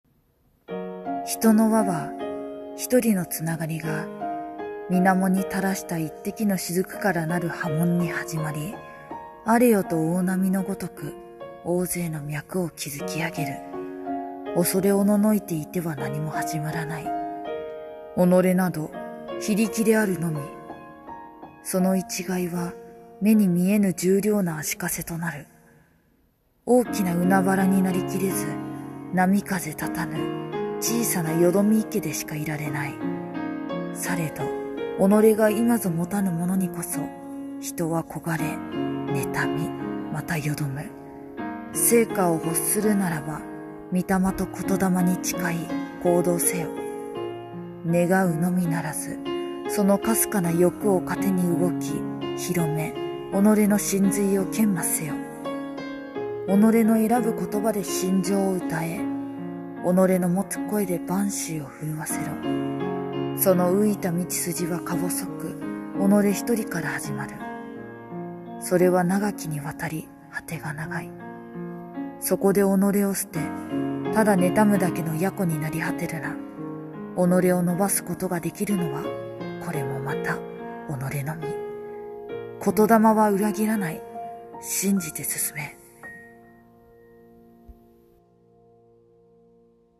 進め 【和風台本】【朗読】